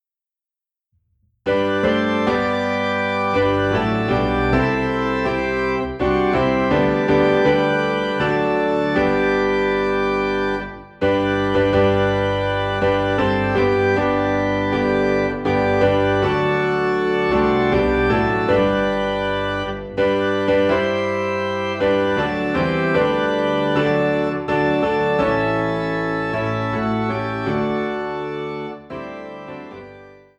3 in G
Organ and piano played together.